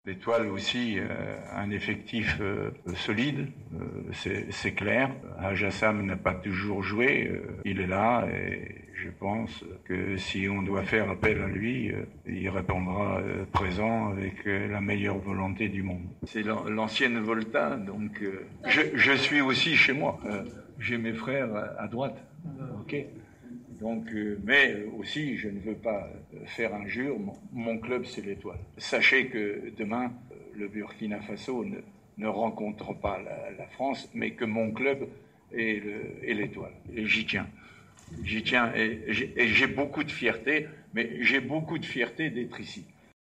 عقد مدرب النجم الساحلي روجي لومار ندوة صحفية اليوم الثلاثاء 12 فيفري 2019 صحبة...